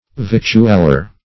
Victualer \Vict"ual*er\ (v[i^]t"'l*[~e]r), n. [F. victuailleur.]